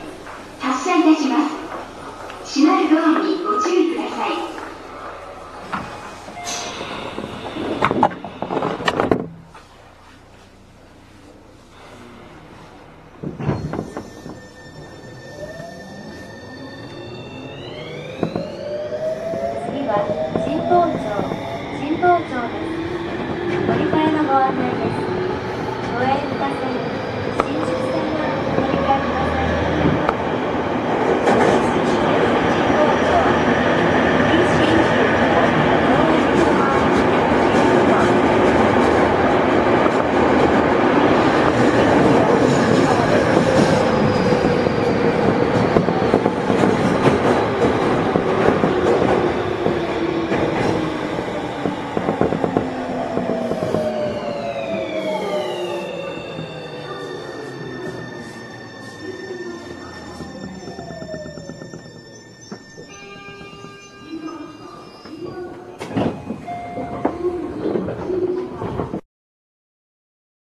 また、現在では初期車を含めた全編成で全電気ブレーキが導入されて0km/hまで回生が効き、停車する直前に日立ＩＧＢＴの全電気ブレーキ車で定番の「ブーン」という音が入ります。
走行音（33609）
収録区間：半蔵門線 九段下→神保町